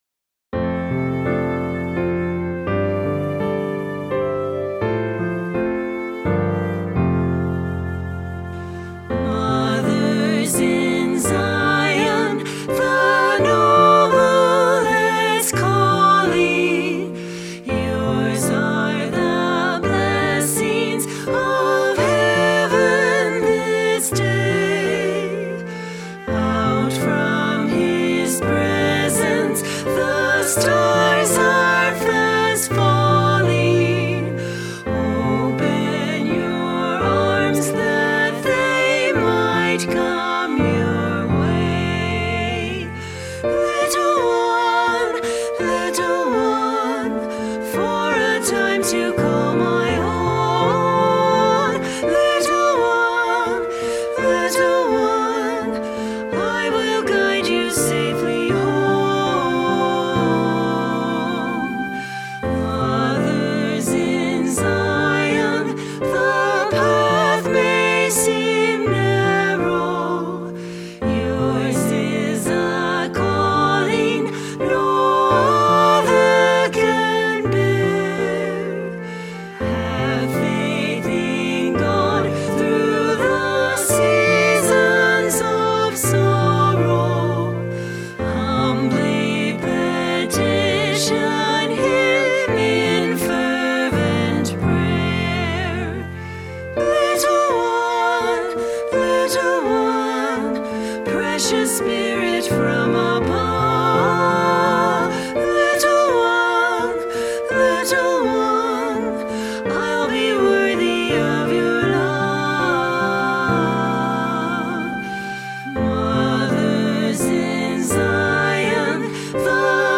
SSA Trio, Flute
SSA Trio or Choir with Piano and Optional Flute
An optional flute solo gently enhances the performance.